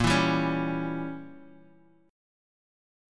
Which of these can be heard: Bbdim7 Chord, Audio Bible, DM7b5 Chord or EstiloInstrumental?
Bbdim7 Chord